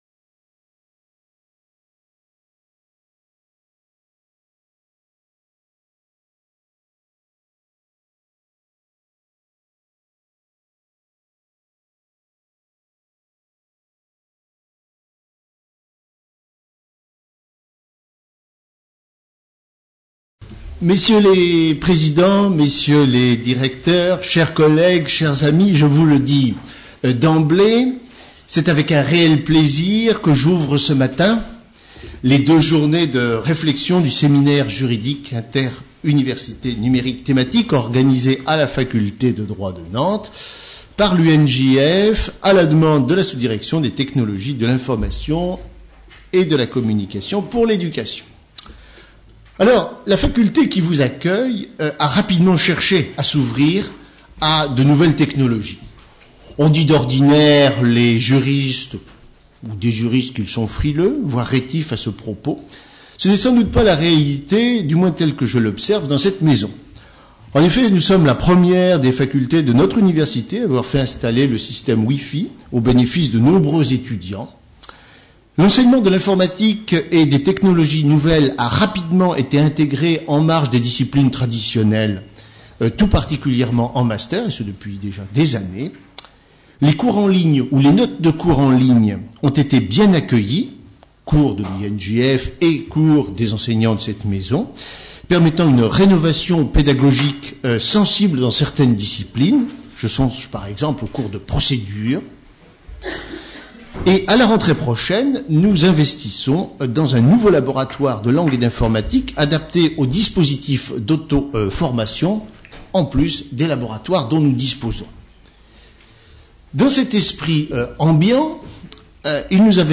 Questions de la salle et réponses des intervenants